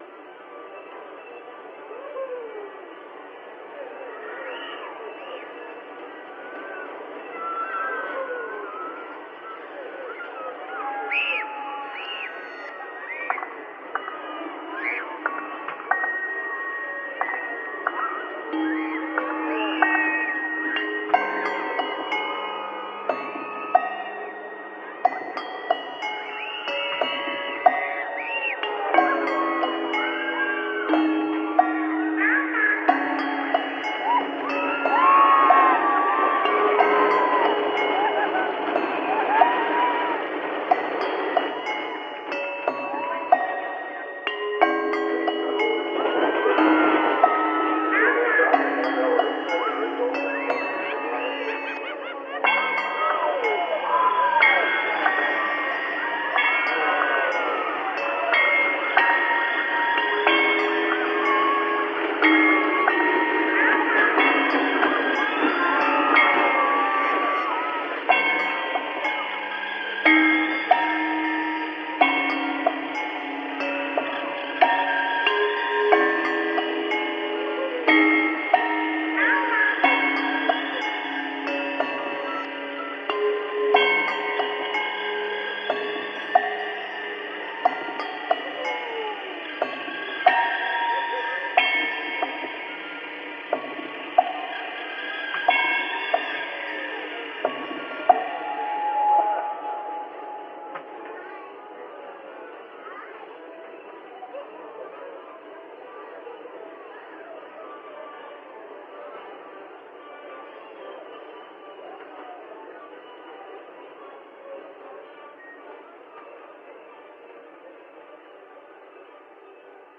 PAYSAGE MUSICAL
En utilisant seulement des objets trouvés transformés,